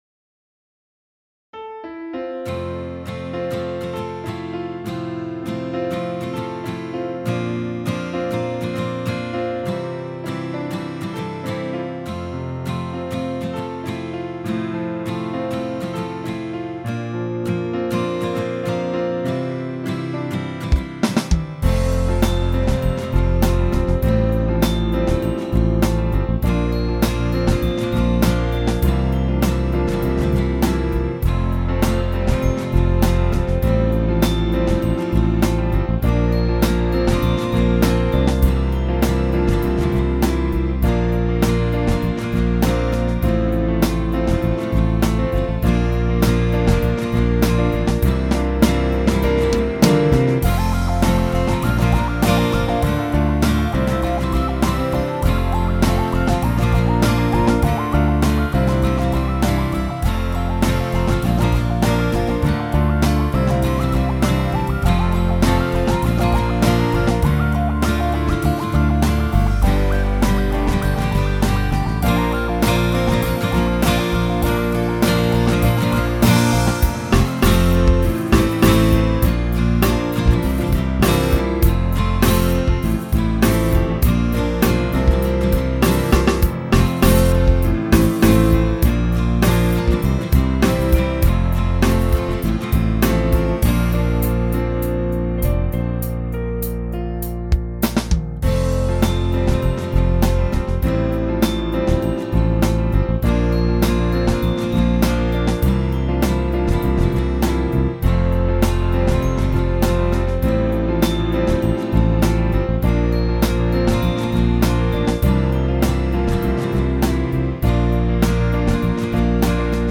02-Obre-els-Ulls-(Base-instrumental)
02-Obre-els-Ulls-Base-instrumental2.mp3